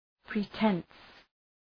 Προφορά
{‘pri:tens, prı’tens}